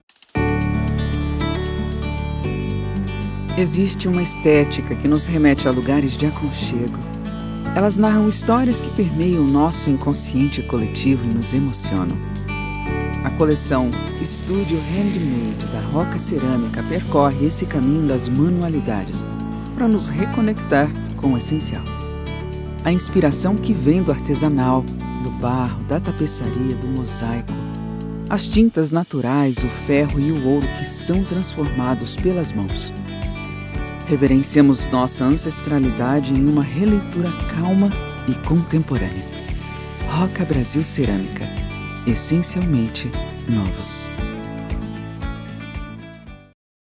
Sprechprobe: Industrie (Muttersprache):
Woman's voice, with peculiar and differentiated timbre. Voice that conveys credibility and confidence in a natural way. Neutral accent, which suits the client's needs and requirements.